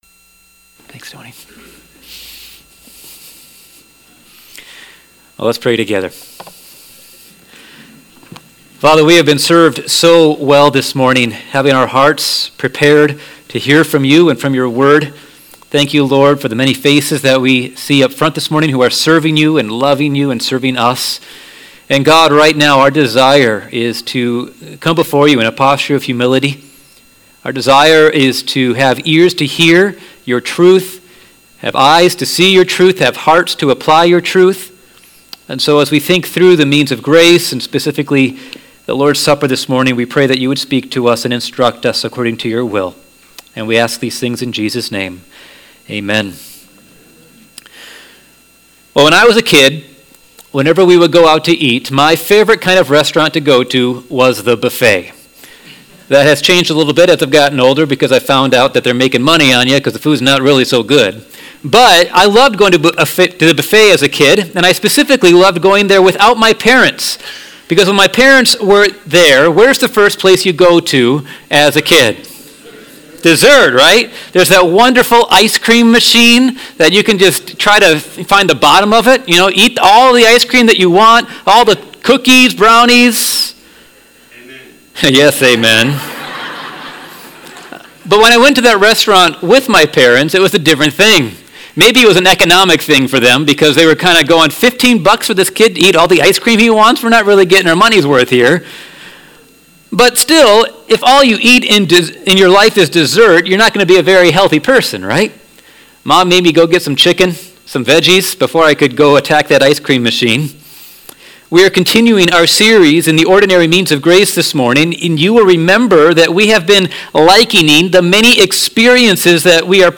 Sermons | Rothbury Community Church